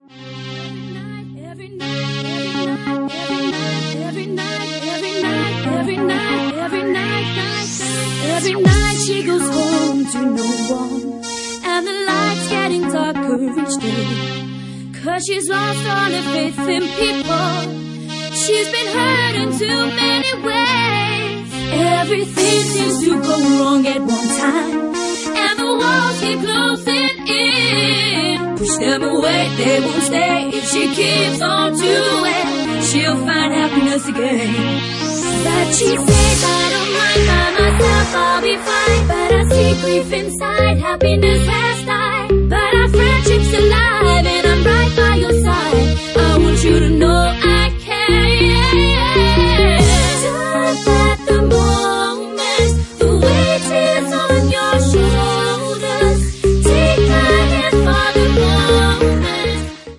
Genre:Bassline House
Bassline House at 141 bpm